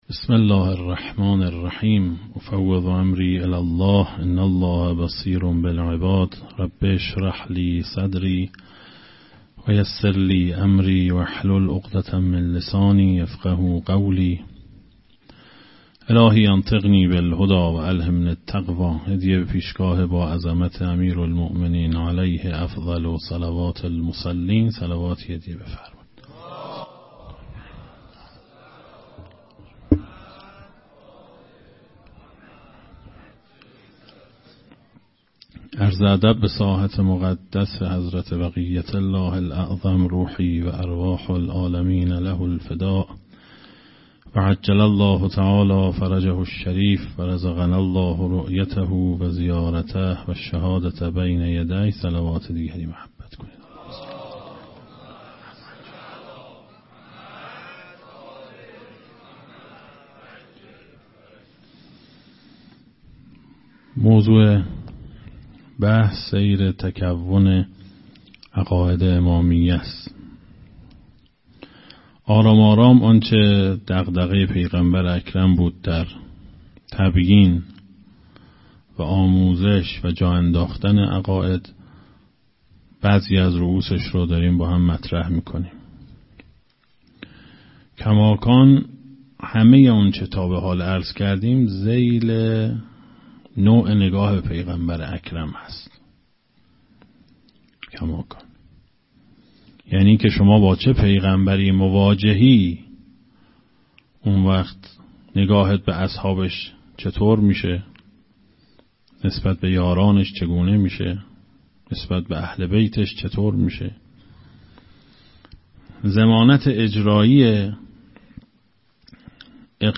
مقدّمه